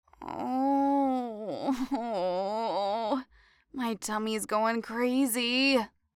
farty3.mp3